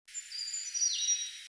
35-2溪頭2011黃胸青鶲s1.mp3
黃胸青鶲 Ficedula hyperythra innexa
錄音地點 南投縣 鹿谷鄉 溪頭
錄音環境 森林
行為描述 鳥叫
收音: 廠牌 Sennheiser 型號 ME 67